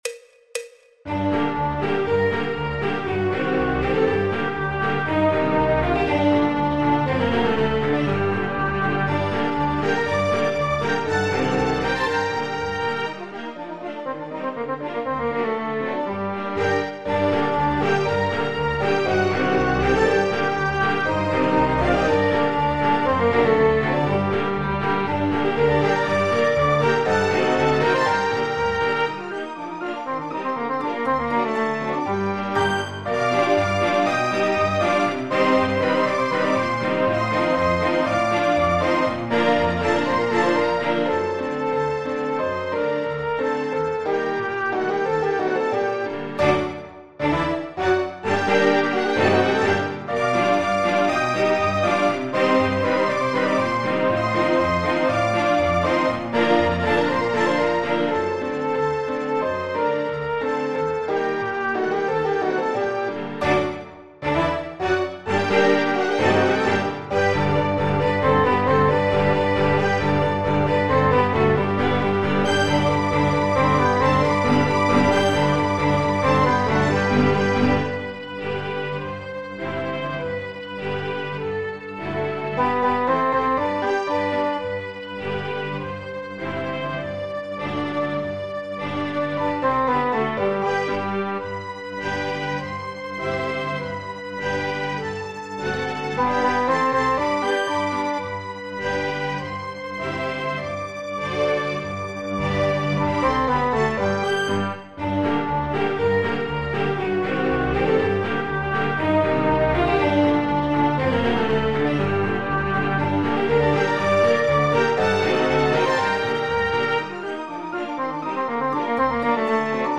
El MIDI tiene la base instrumental de acompañamiento.
Corno Inglés, Corno inglés
Popular/Tradicional